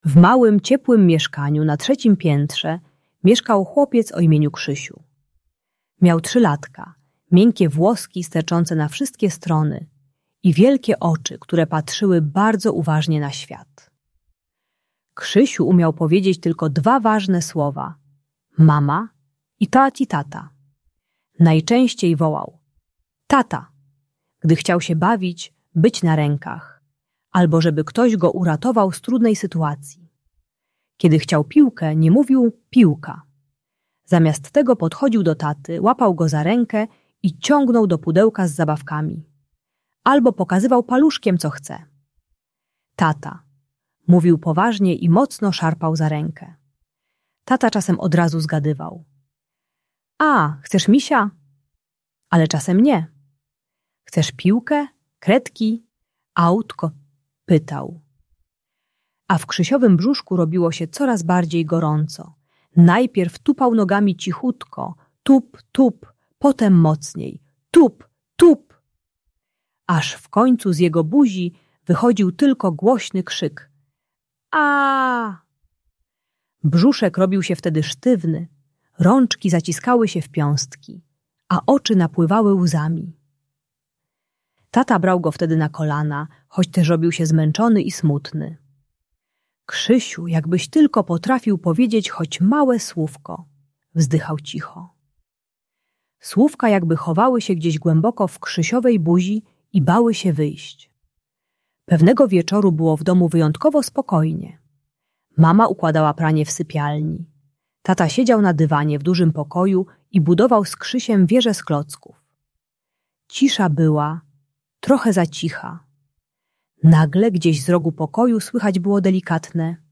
Audiobajka o trudnościach z mówieniem pomaga dziecku które krzyczy zamiast mówić i uczy techniki małych dźwięków - stopniowego budowania słów od pojedynczych głosek.